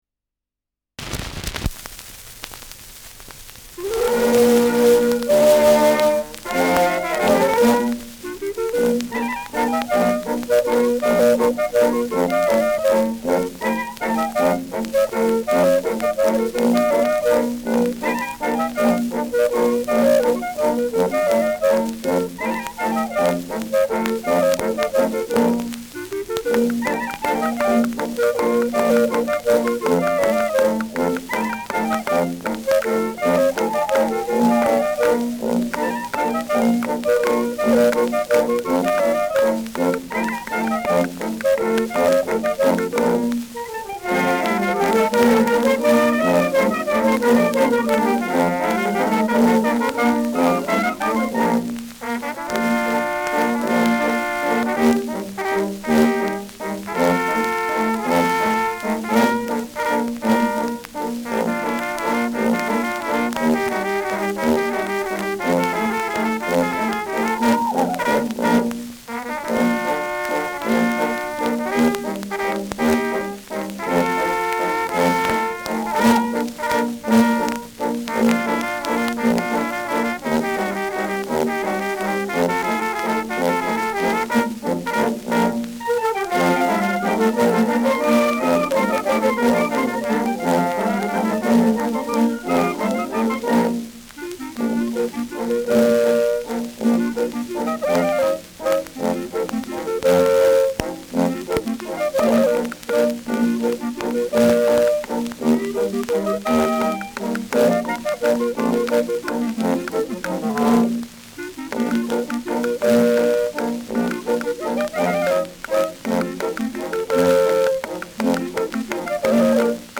Schellackplatte
Stärkeres Grundrauschen : Gelegentlich leichtes bis stärkeres Knacken : Verzerrt an lauten Stellen
[München] (Aufnahmeort)